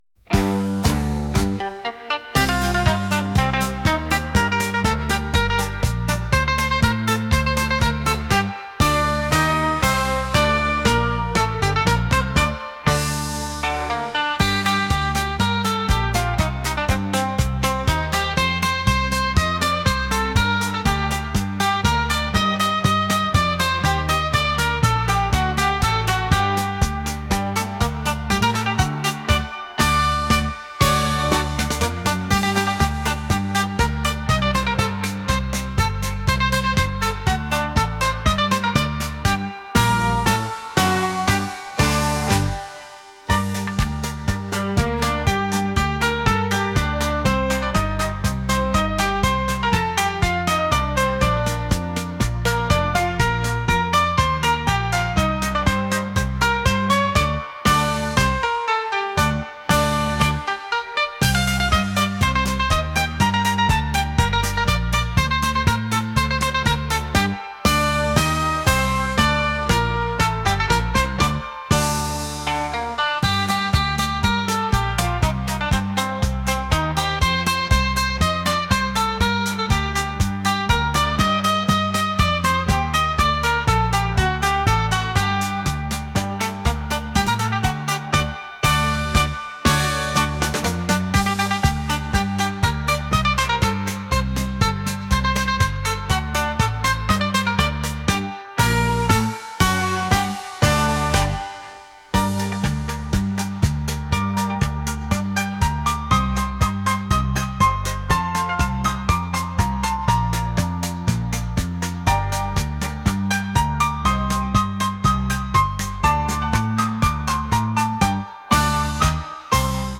pop | acoustic | lofi & chill beats